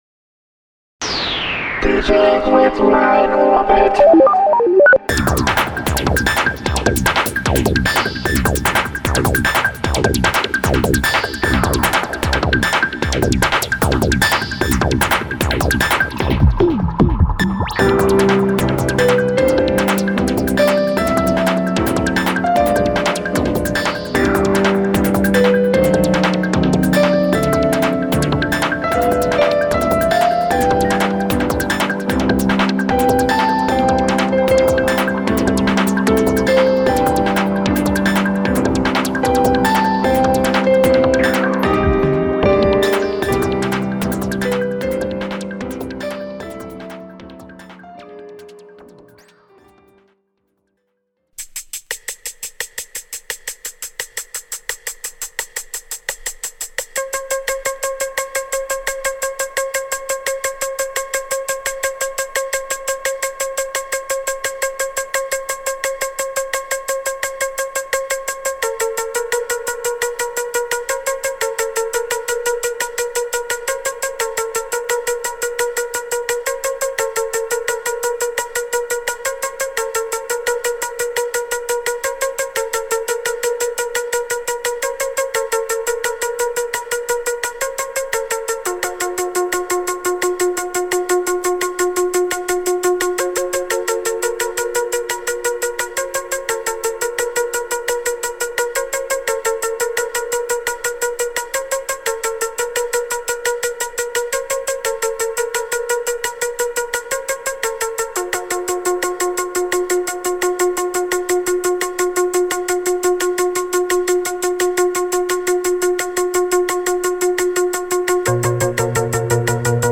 Focused on late 90s – early 2000s Electro Tunes.